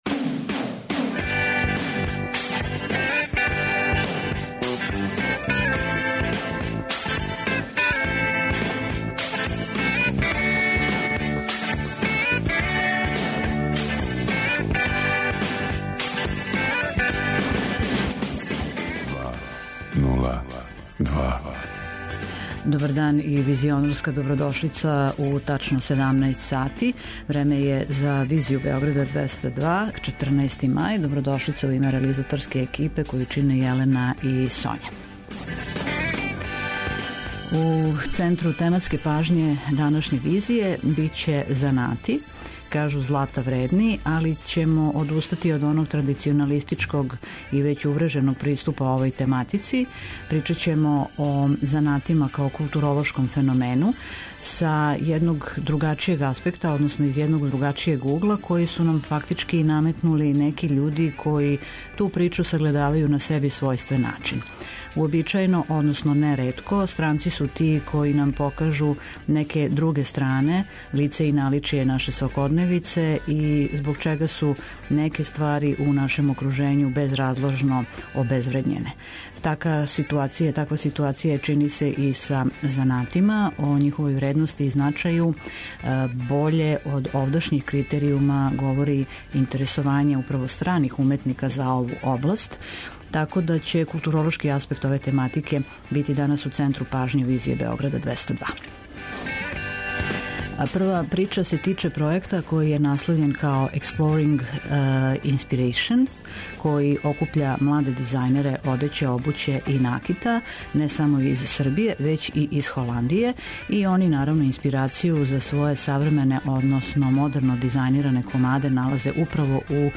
Социо-културолошки магазин, који прати савремене друштвене феномене. Тема: занат - културолошки феномен.